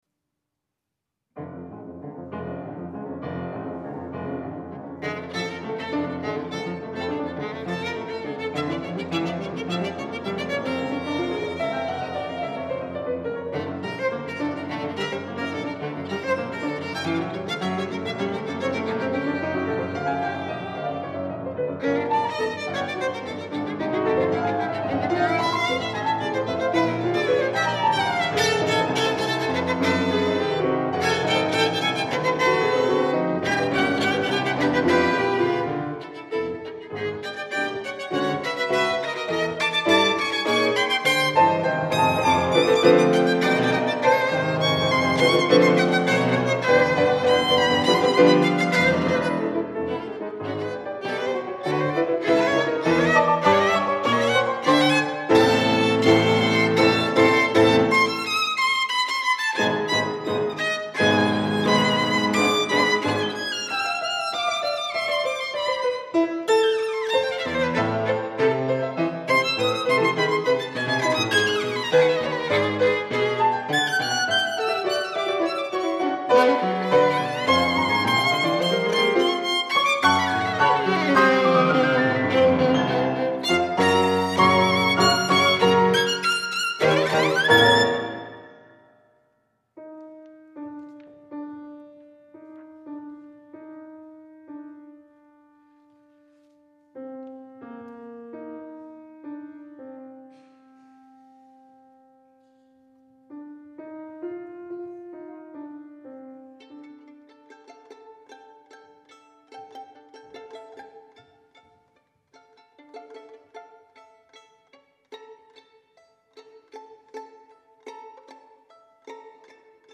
скрипка
фотепиано